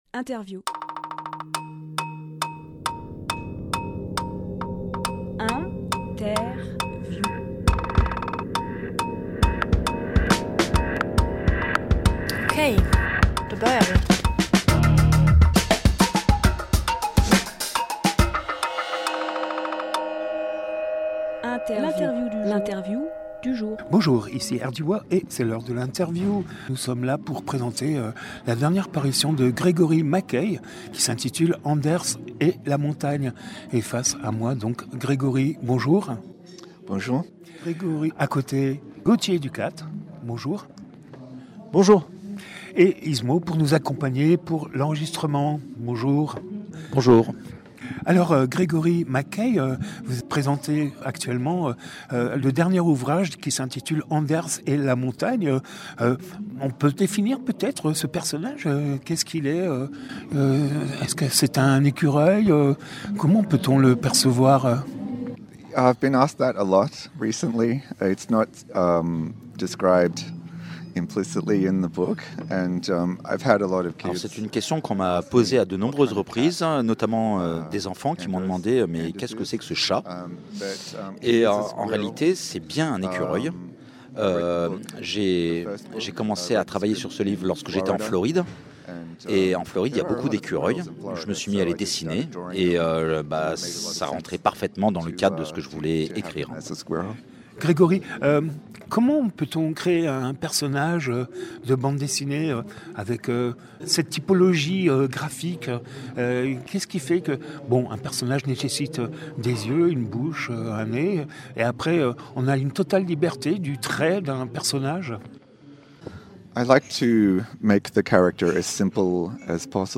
Emission - Interview
lieu : Studio RDWA